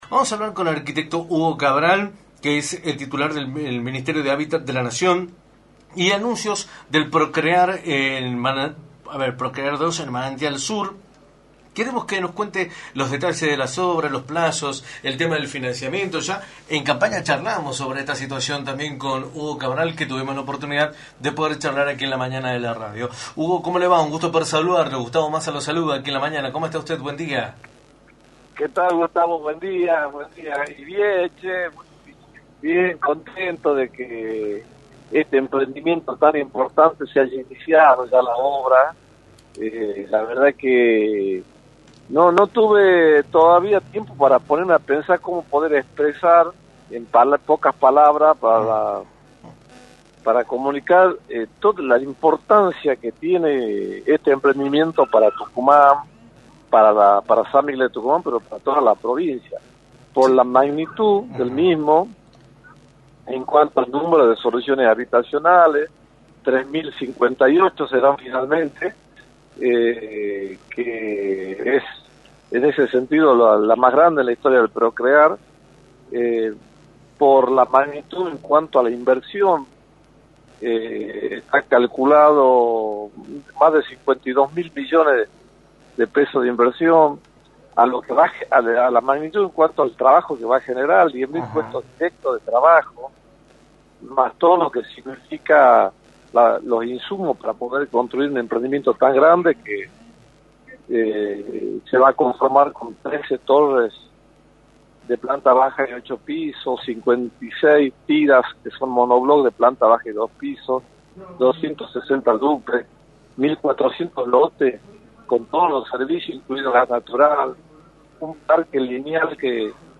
Hugo Cabral, titular del Ministerio de Hábitat de la Naciona, informó en Radio del Plata Tucumán, por la 93.9, lo que se debe tener en cuenta en relación al PROCREAR II en el Manantial Sur, el avance de las obras, el plazo de ejecución y el financiamiento de estas soluciones habitacionales.